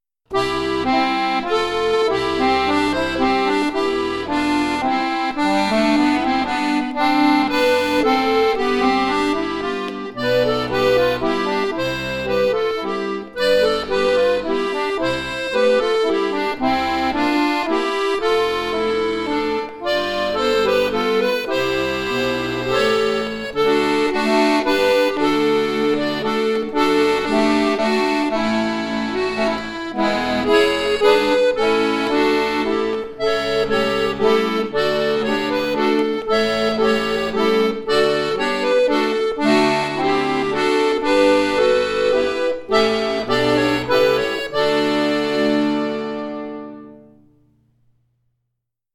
Romantisches Volkslied
arrangiert für Akkordeon-Duo